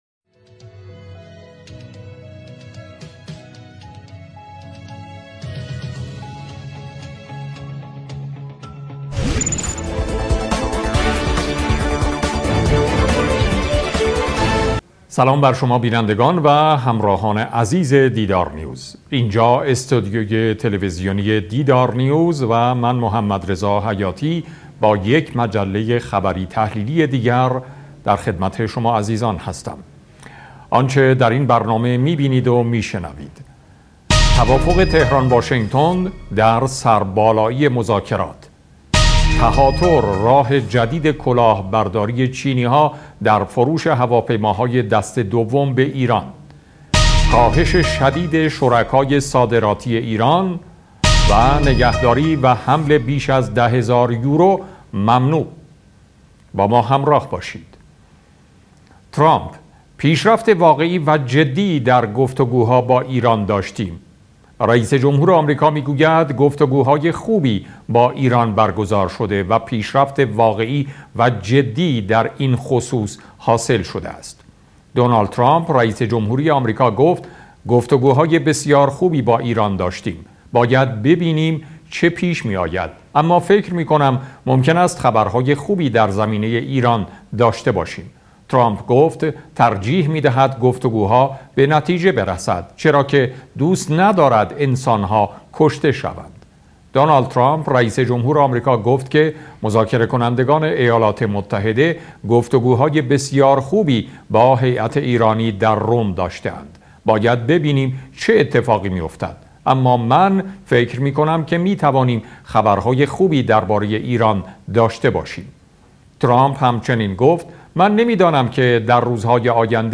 دیدارنیوز: صدای برنامه مجله خبری، مناظرات و دیگر گفتگوهای دیدار را می‌توانید در قسمت صدای دیدار بشنوید.